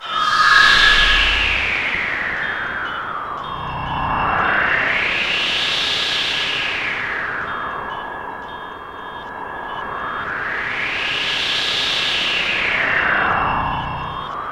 SI2 ALIEN04L.wav